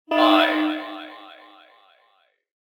selfdestructfive.ogg